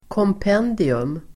Ladda ner uttalet
Uttal: [kåmp'en:dium]